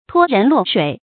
拖人落水 注音： ㄊㄨㄛ ㄖㄣˊ ㄌㄨㄛˋ ㄕㄨㄟˇ 讀音讀法： 意思解釋： 猶言拖人下水。